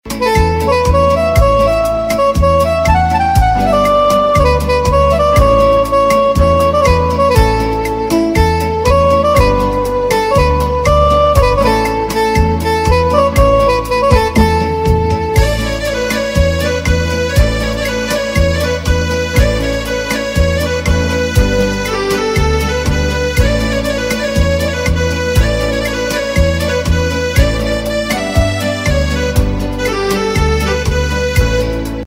Instrumental MP3 Ringtones